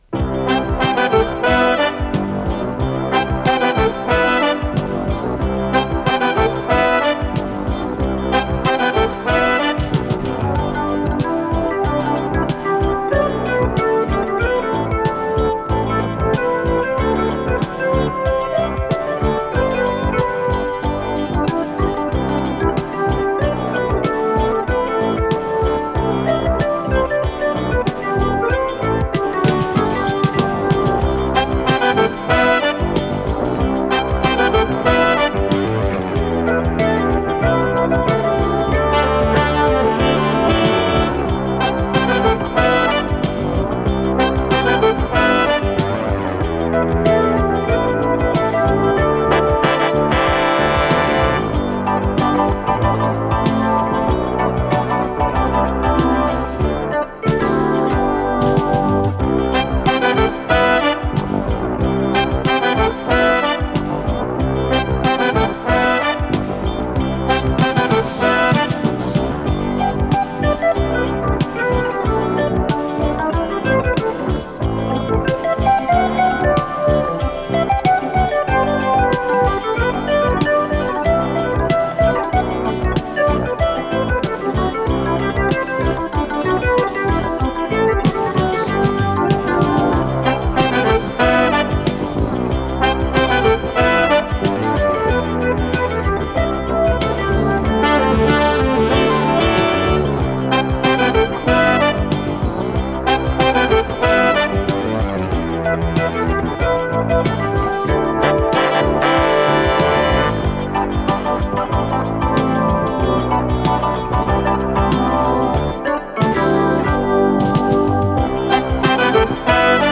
соло на электрофортепиано
ВариантДубль моно